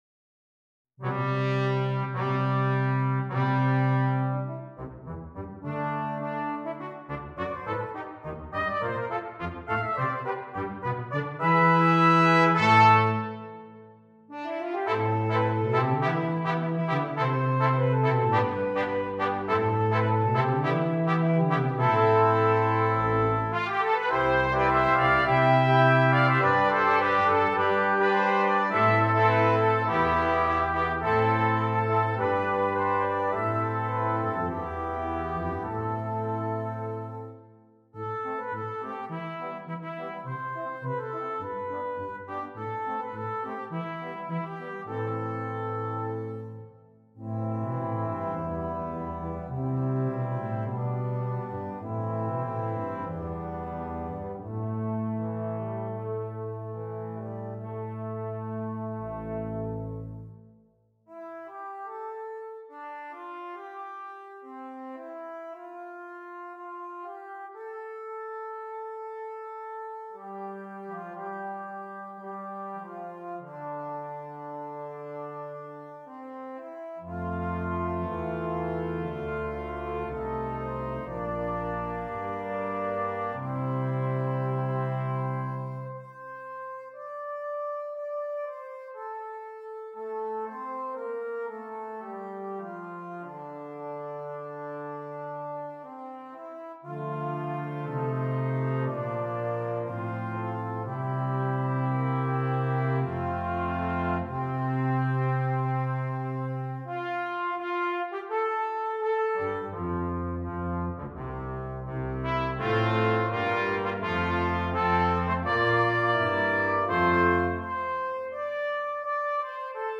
Brass Quintet
rousing, beautiful and inspirational.